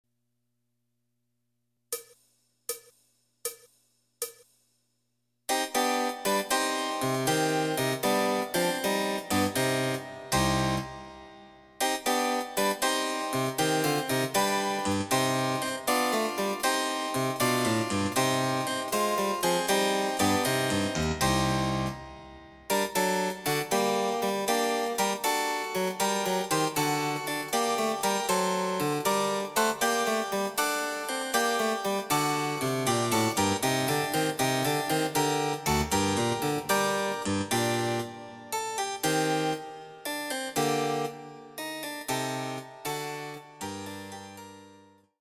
その他の伴奏
Electoric Harpsichord